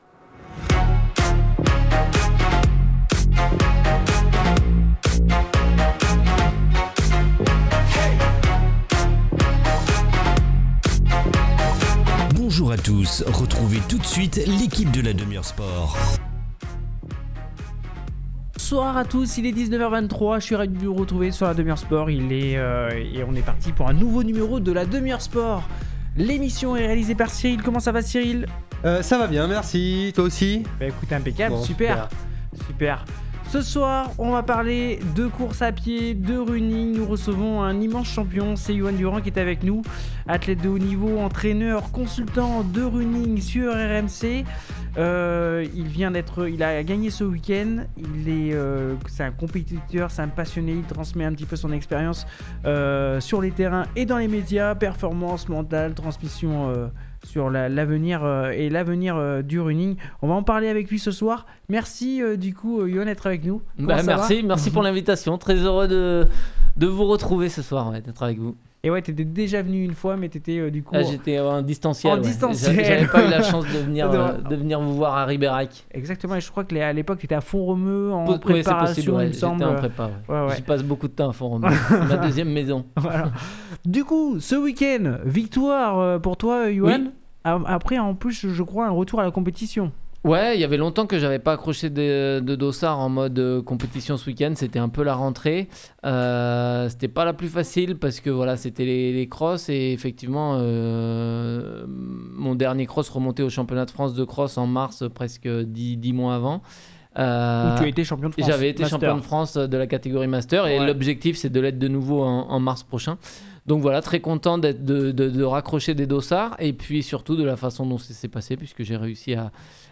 Un échange passionnant sur la performance, le mental et la transmission.